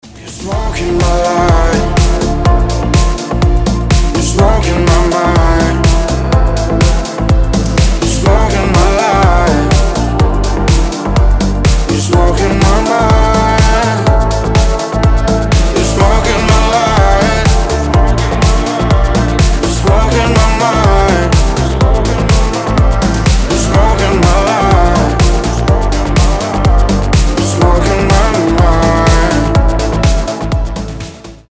• Качество: 320, Stereo
поп
deep house
дуэт